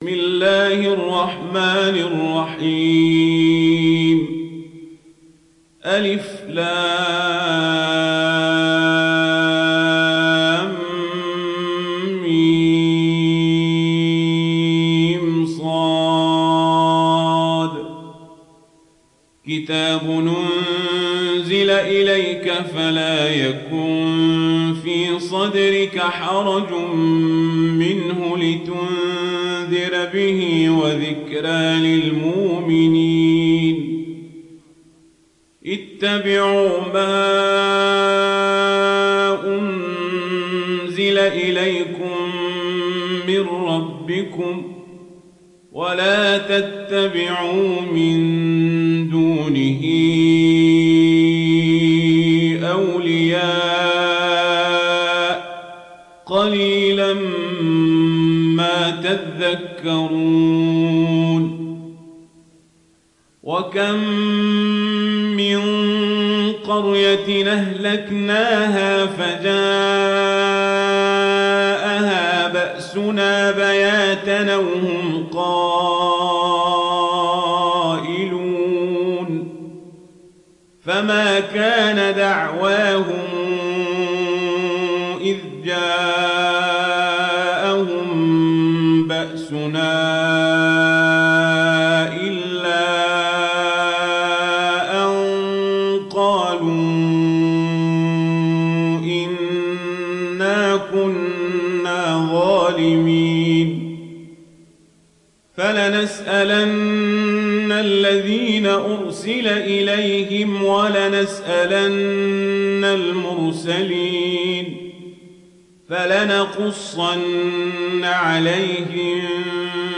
تحميل سورة الأعراف mp3 بصوت عمر القزابري برواية ورش عن نافع, تحميل استماع القرآن الكريم على الجوال mp3 كاملا بروابط مباشرة وسريعة